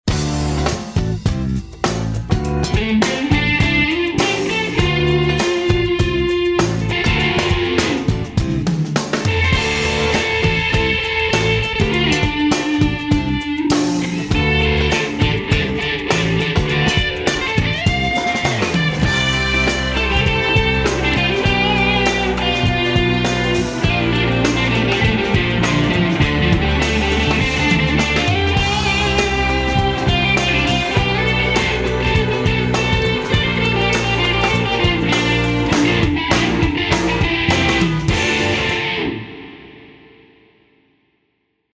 Jam tracks -- nahrajte si vlastne gitarove stopy a zverejnite!